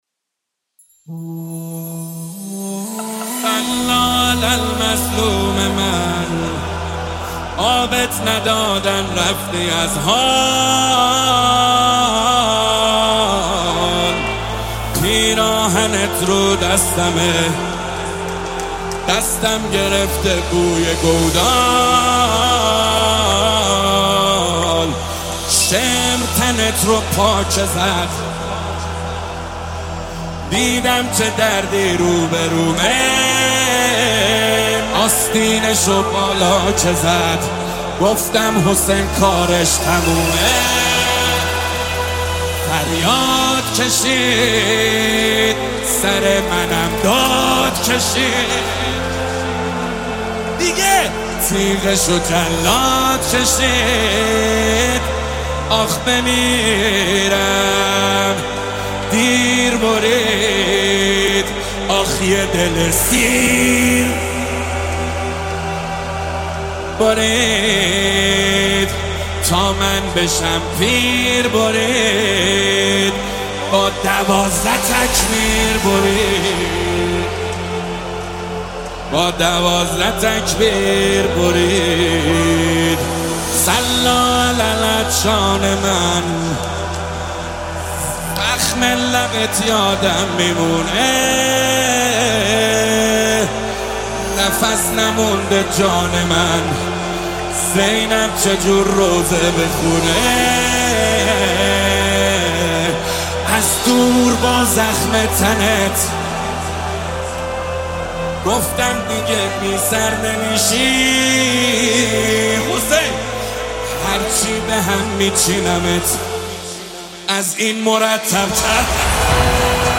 مداحی ماه محرم